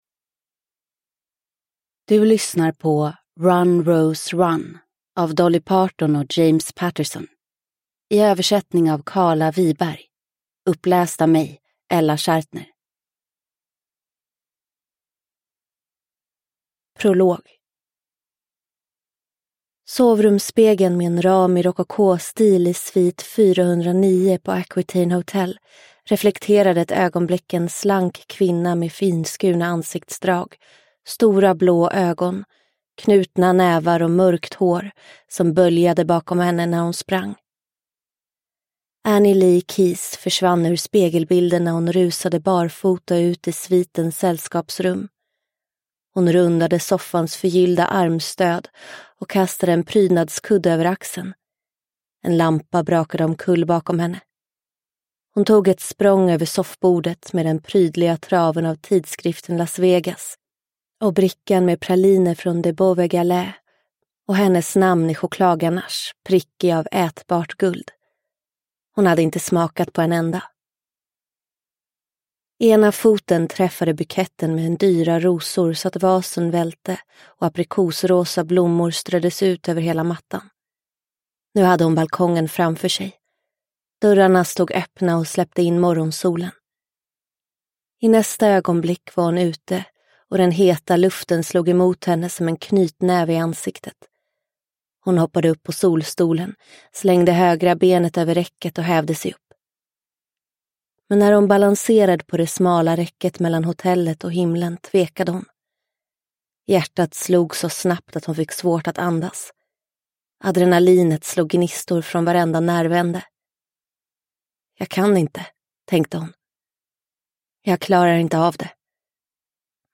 Run, Rose, Run – Ljudbok – Laddas ner